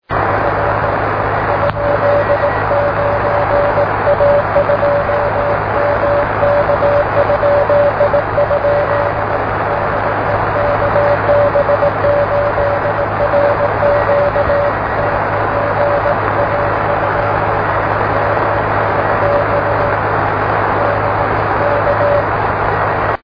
This is not a typical EME signal; it is much less intensive.